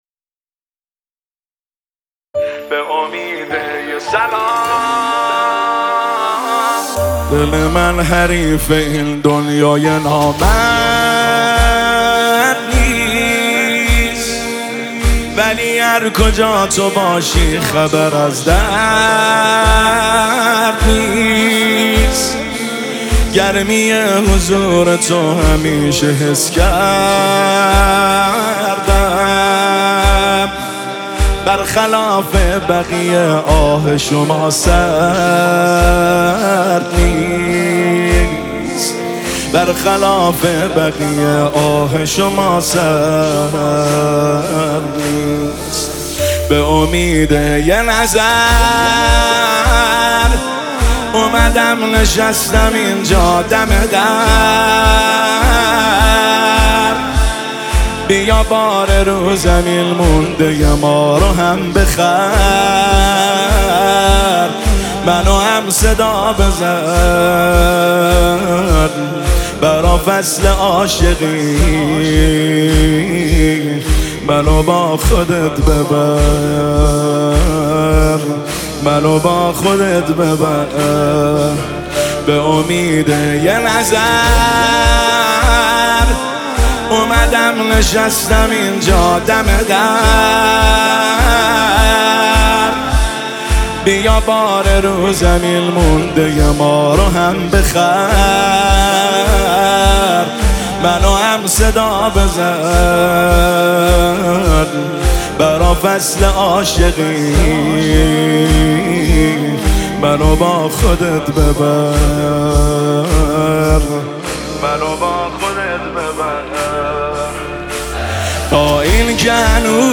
نماهنگ استودیویی